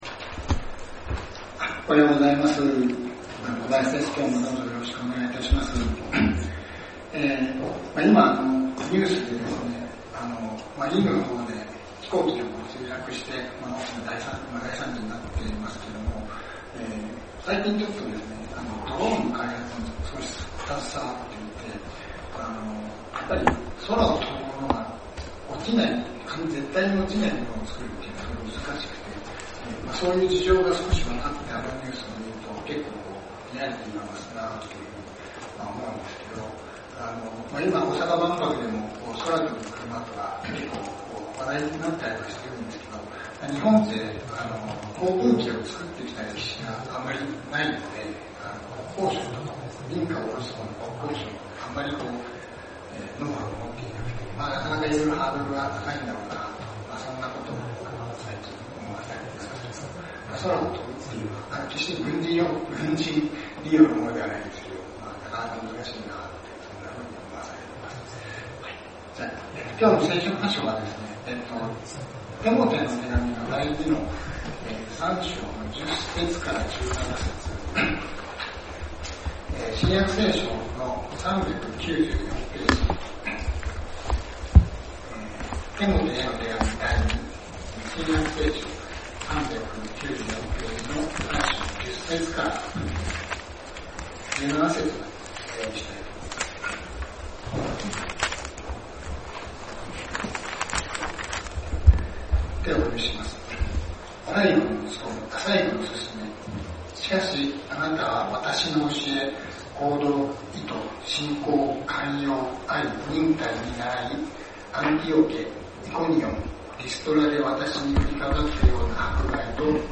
先週 ，東京集会で行われた礼拝で録音された建徳です。